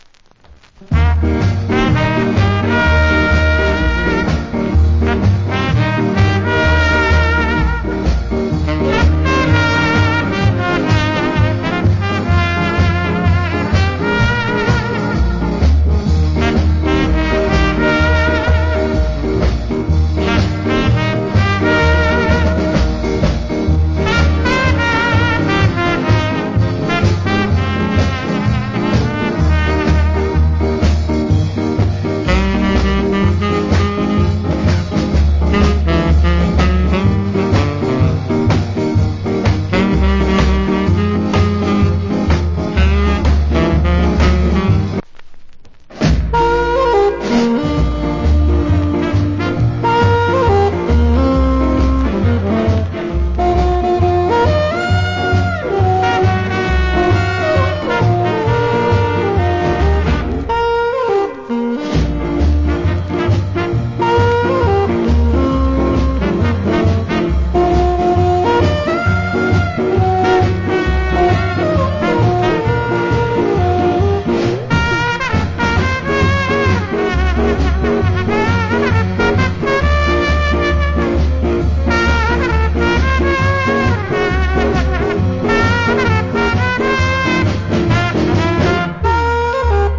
Cool Ska Inst.